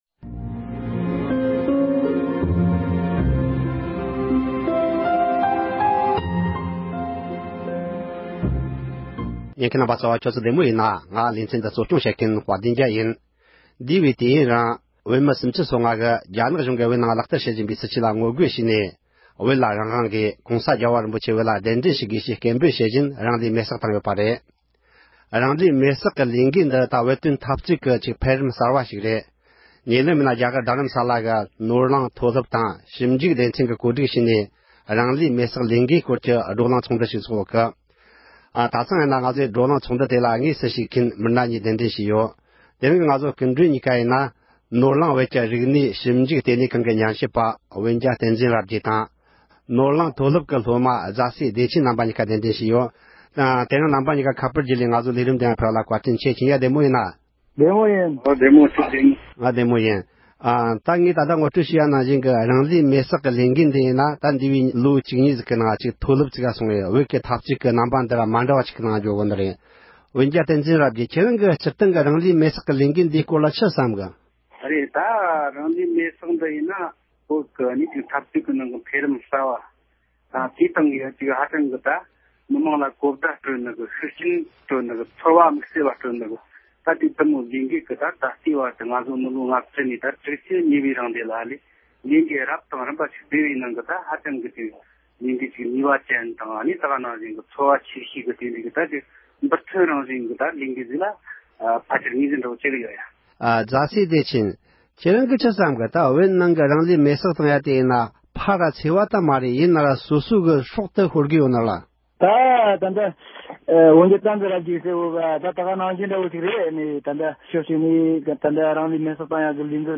རང་ལུས་མེ་སྲེག་གི་ལས་འགུལ་སྐོར་འབྲེལ་ཡོད་མི་སྣ་ཁག་དང་མཉམ་དུ་གླེང་མོལ་ཞུས་པ།